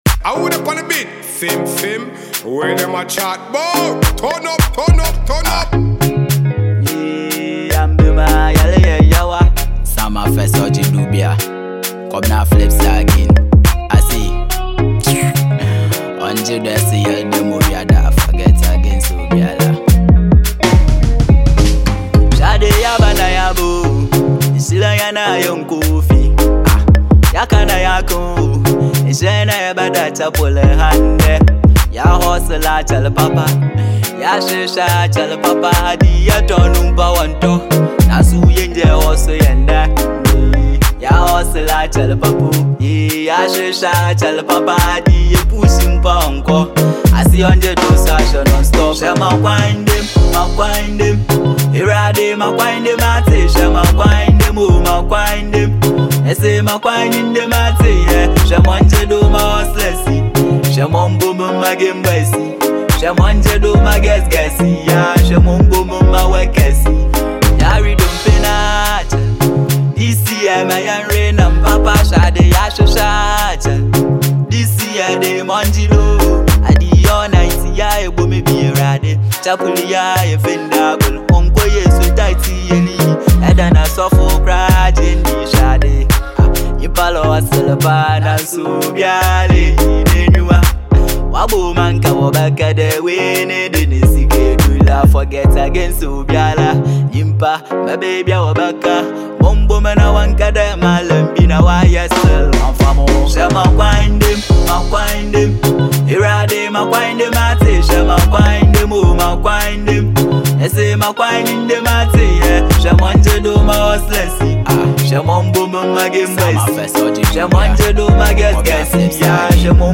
christmas inspirational banger